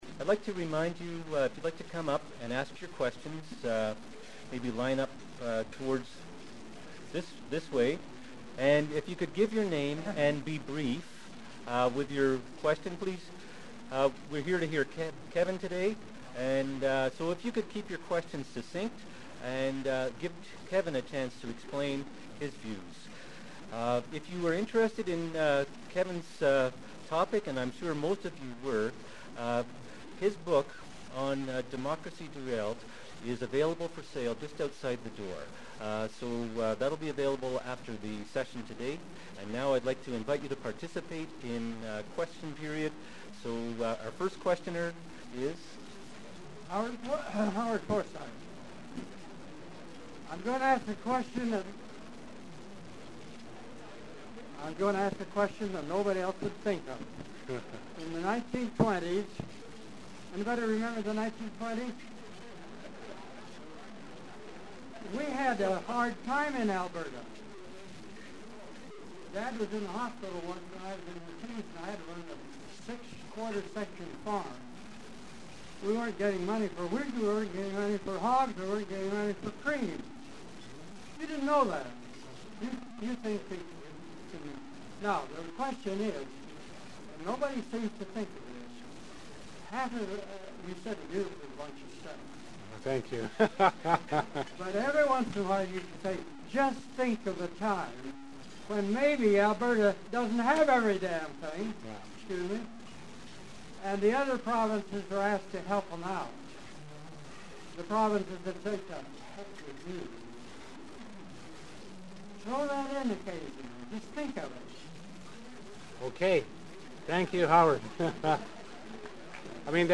Kevin Taft will speak on the need to put Alberta on a path toward an environmentally, financially, and socially sustainable future.